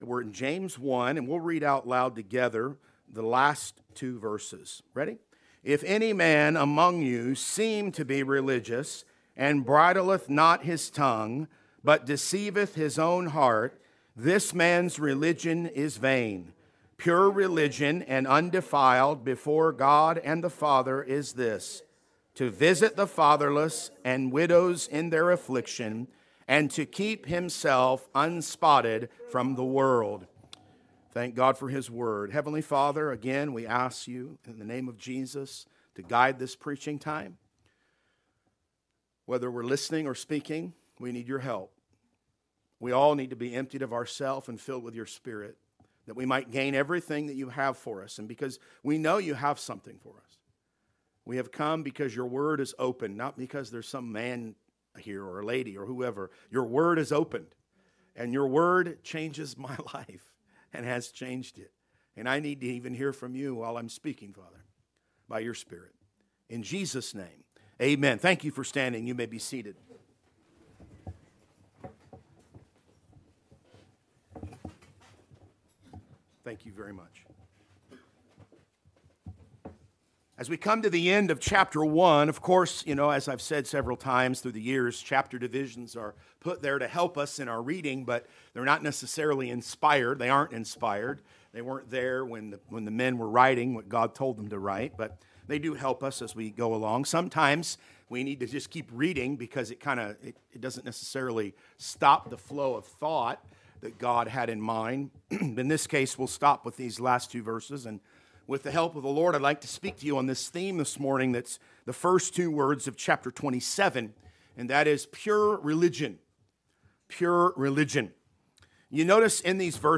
Sermons | Anchor Baptist Church